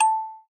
AMUSICBOX
A box childish cute ding freesampler innocent metallic sound effect free sound royalty free Music